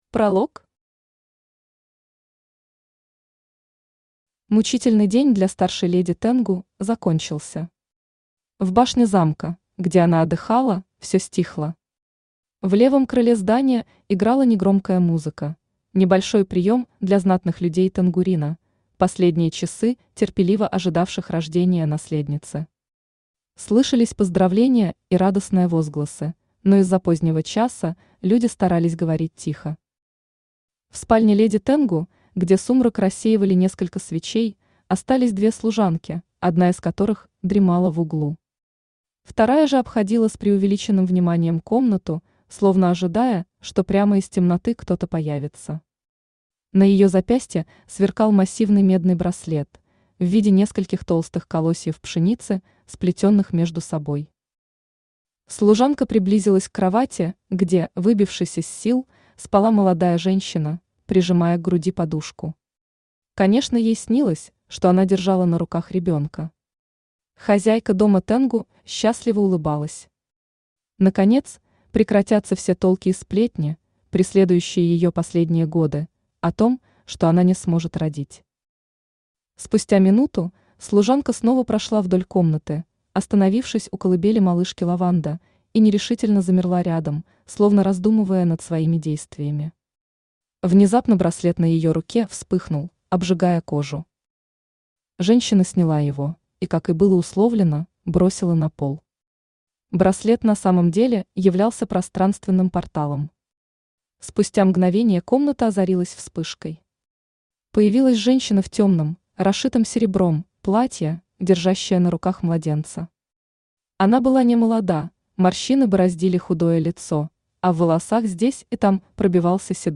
Aудиокнига Драконья омела Автор Татьяна Абиссин Читает аудиокнигу Авточтец ЛитРес.